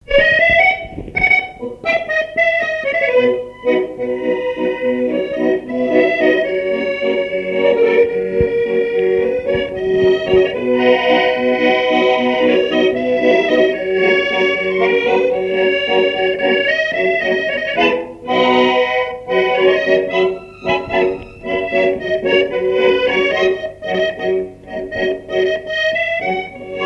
Valse-bourrée
Coëx
danse : valse
Pièce musicale inédite